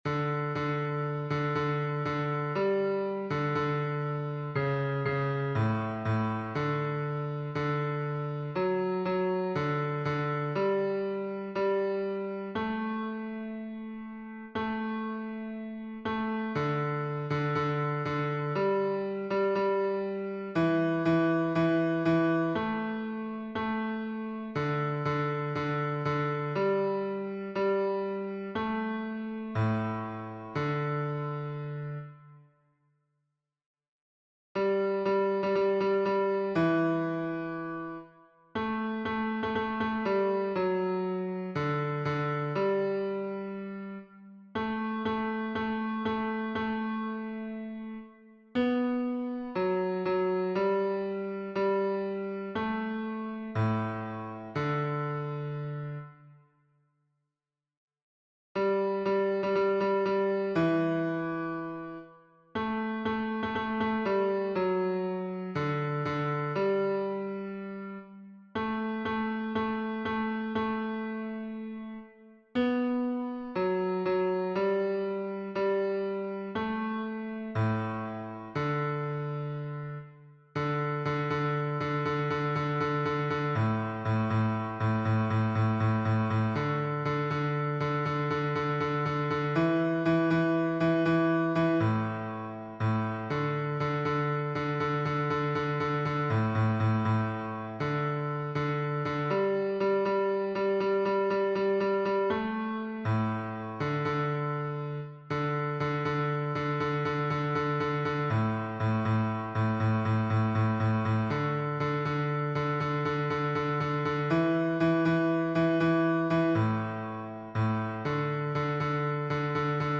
#27: How Excellent Is Thy Loving Kindness — Bass Audio | Mobile Hymns
Key signature: D major (2 sharps) Time signature: 2/2 and 12/8 Meter: Irregular
How_Excellent_Is_Thy_Loving_Kindness_bass.mp3